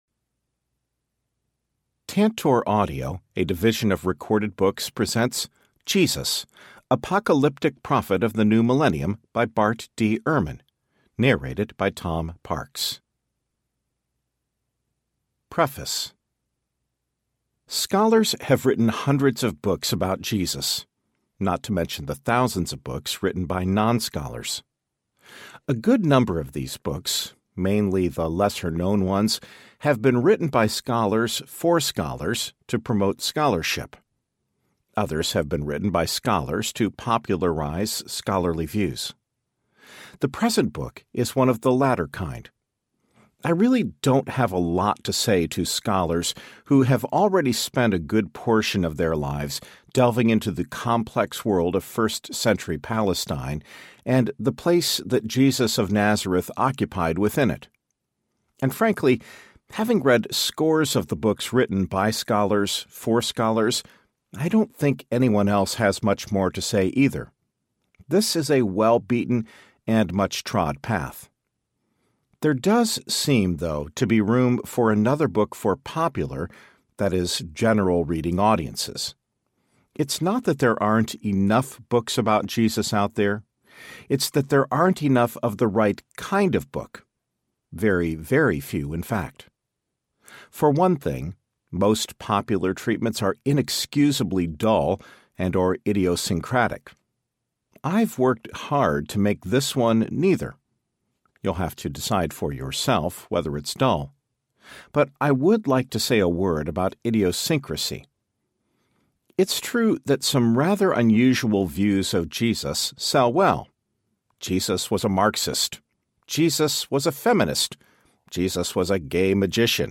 Jesus Audiobook
Narrator
12.4 Hrs. – Unabridged